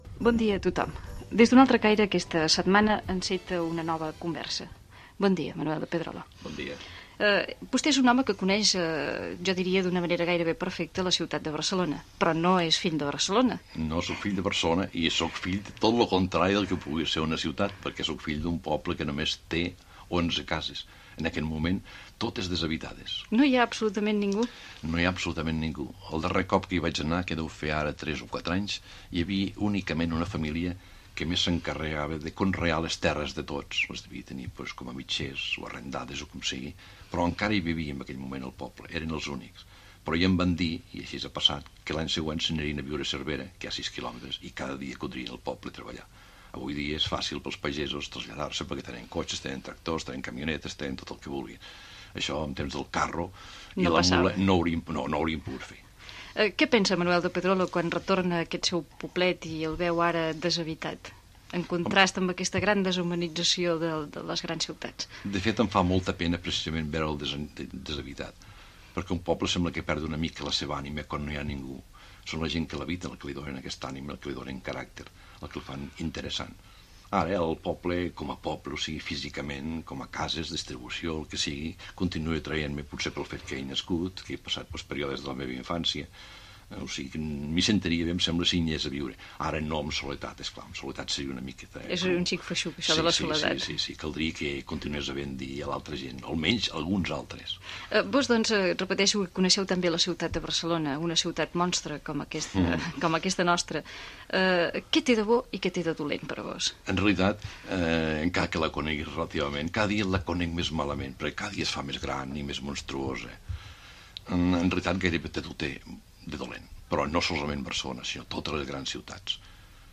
20f84d92ed6ffeea2a8f66ef9f1badfb210d84b6.mp3 Títol Ràdio 4 Emissora Ràdio 4 Cadena RNE Titularitat Pública estatal Nom programa Un altre caire Descripció Identificació del programa i entrevista a l'escriptor Manuel de Pedrolo, guardonat amb el Premi d'Honor de les Lletres Catalanes. Parla del seu poble, la ciutat de Barcelona, la seva infància, la Guerra Civil espanyola, i la recuperació de les institucions catalanes